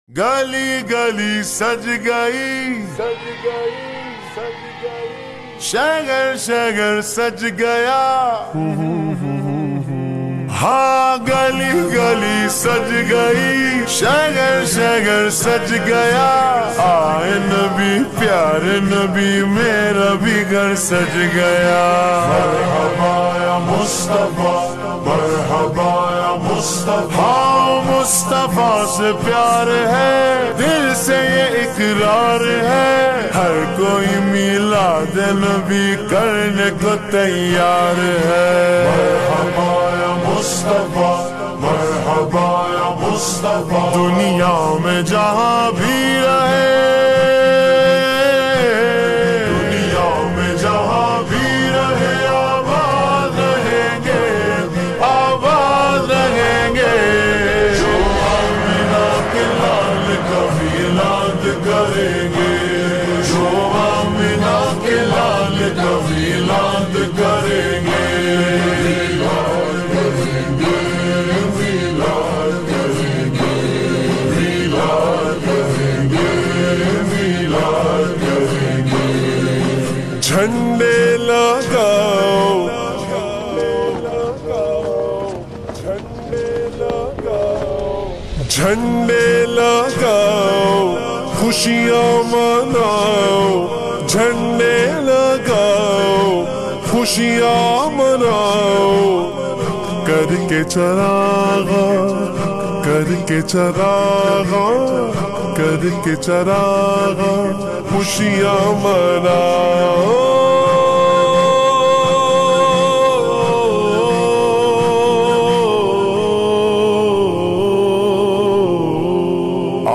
Naat
SLOW AND REVERB NAAT